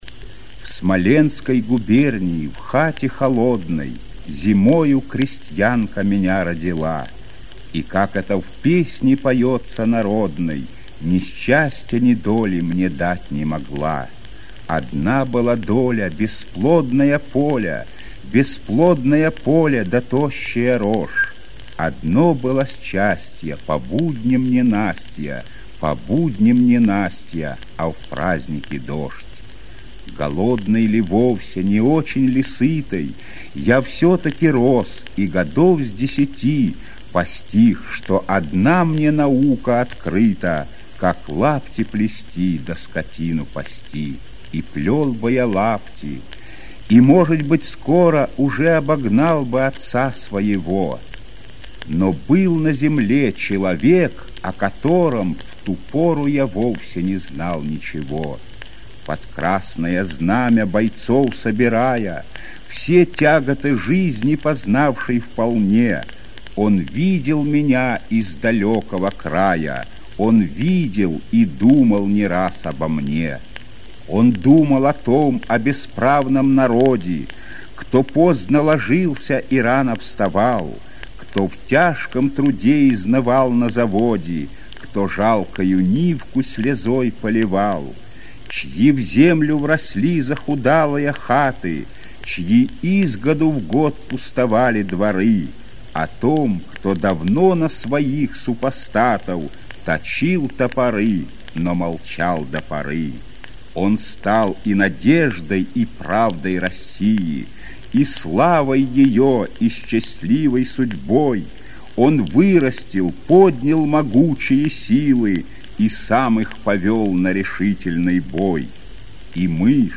2. «Михаил Исаковский – Дума о Ленине (читает автор)» /